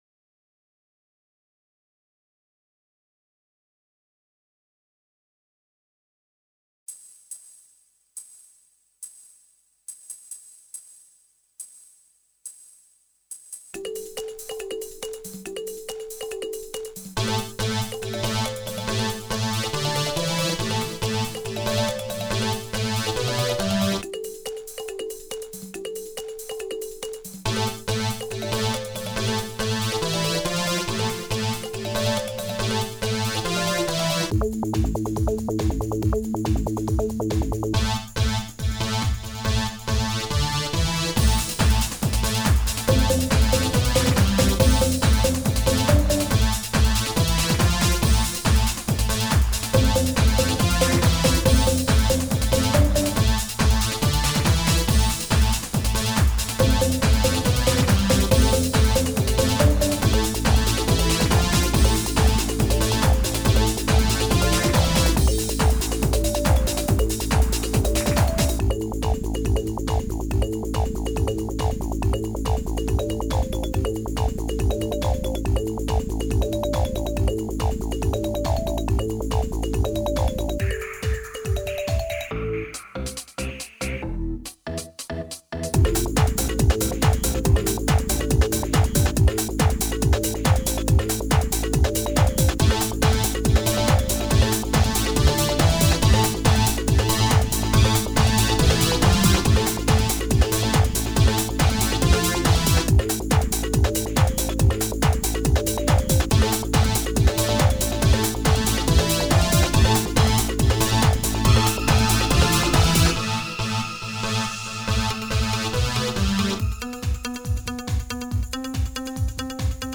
Y como el movimiento se demuestra andando, aquí tenéis la primera pista que compuse con un editor digital, hace ya la friolera de doce años.